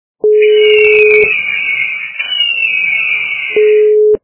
» Звуки » Природа животные » Ястреб - Крик
Звук Ястреб - Крик